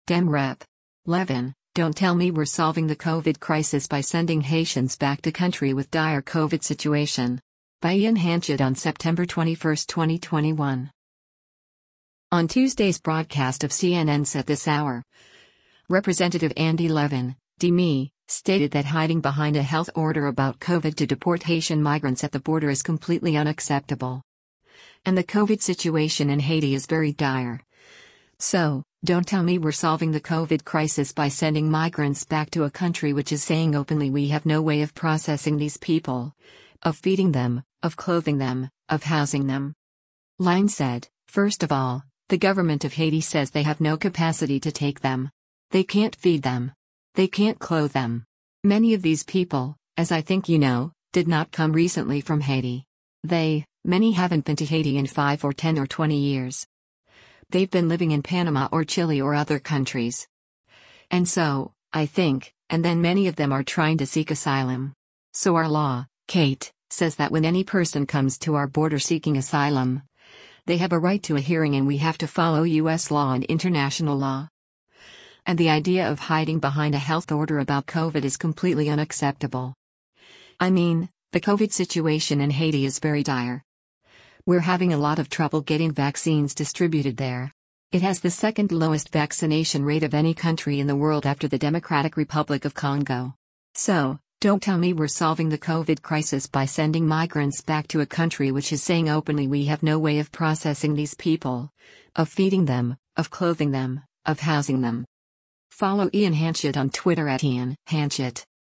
On Tuesday’s broadcast of CNN’s “At This Hour,” Rep. Andy Levin (D-MI) stated that “hiding behind a health order about COVID” to deport Haitian migrants at the border “is completely unacceptable.”